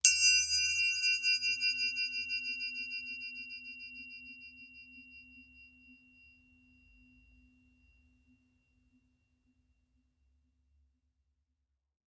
Triangle Sml Strike Spins 1